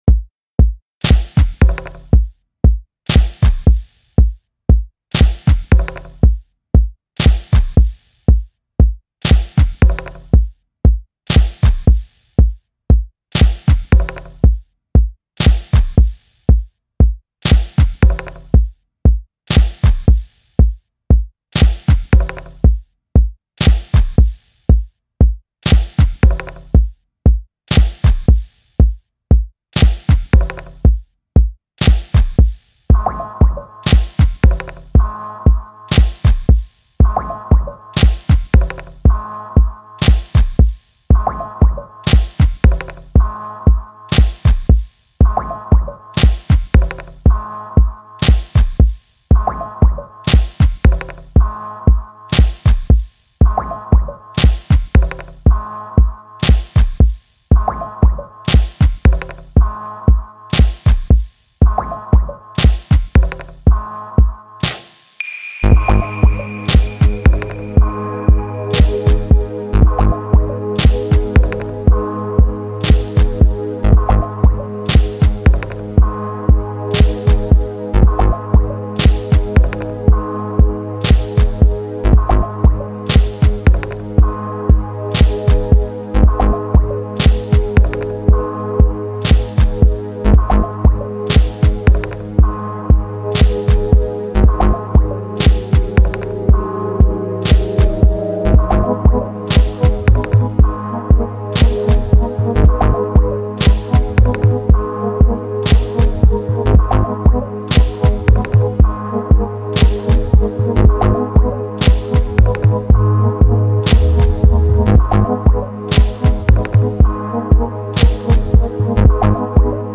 groovy anthem